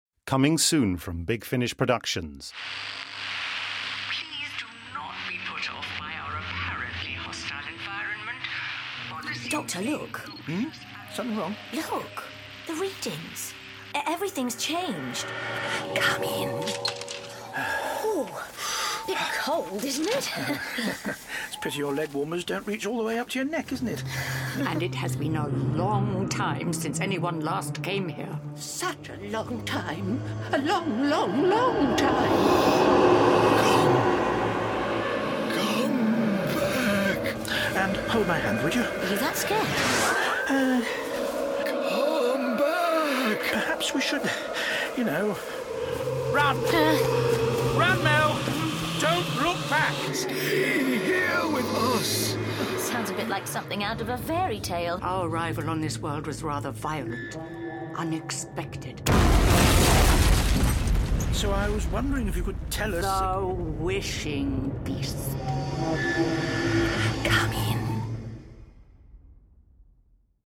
Award-winning, full-cast original audio dramas